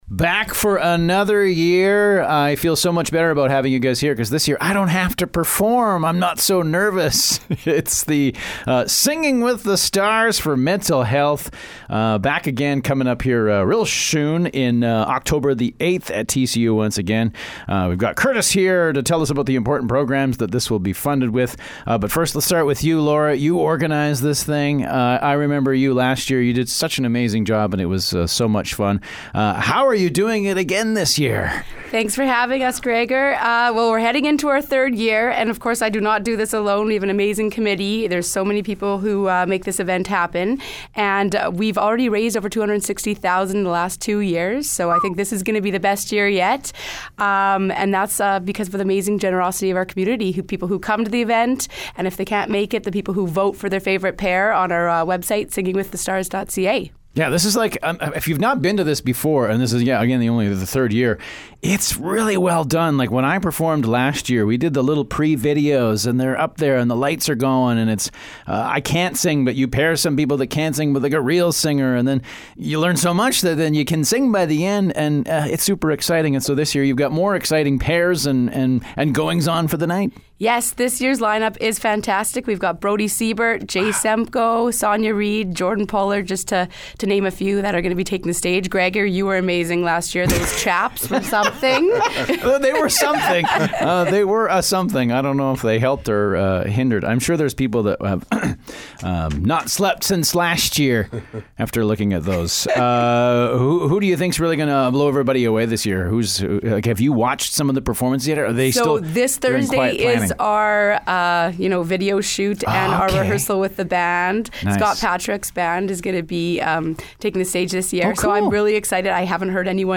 swts-interview.mp3